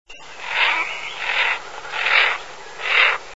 Harle bièvre, mergus merganser